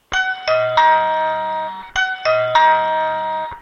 9 belklanken met regelbaar volume
-- M-E-6x0 belklank-keuzemogelijkheden (klik op bel):